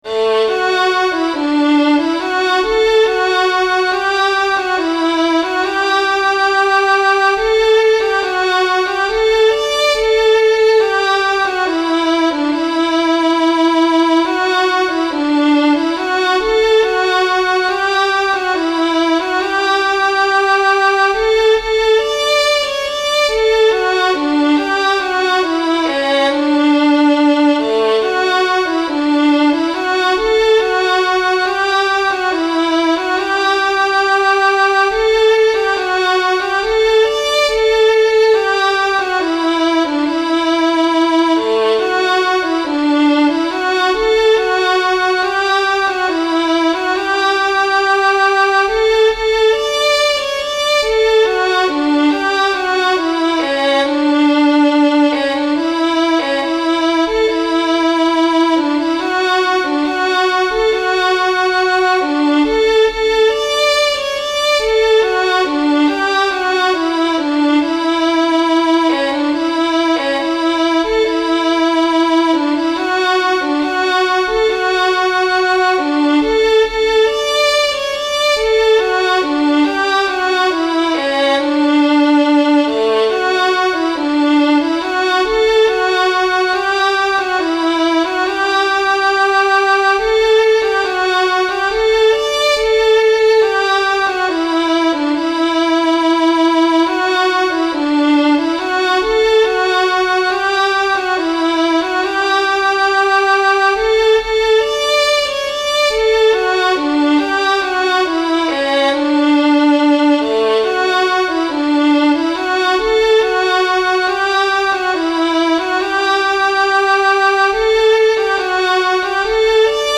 This one is "Frolocking in the Glen", a light, airy tune.